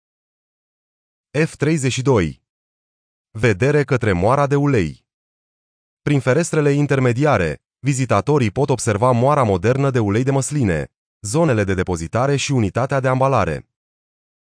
Tur ghidat audio